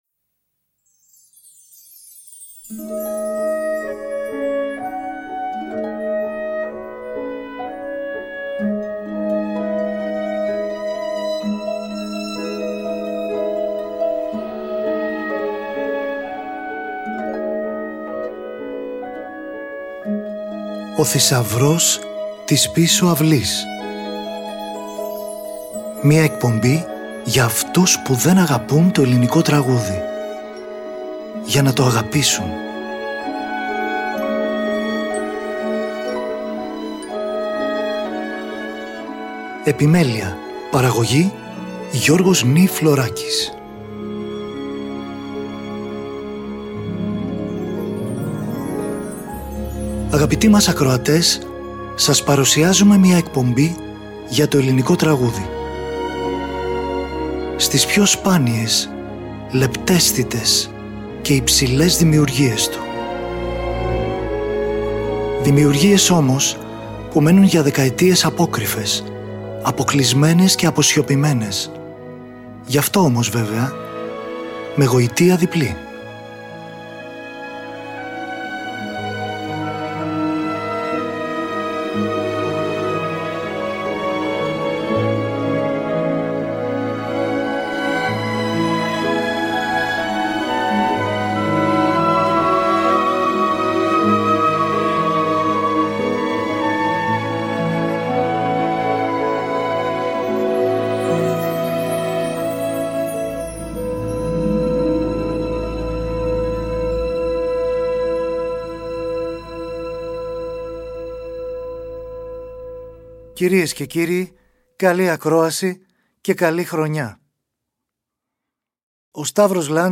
τρομπέτα και φλικόρνο
κοντραμπάσο
τύμπανα
πιάνο, μελόντικα και μεταλλόφωνο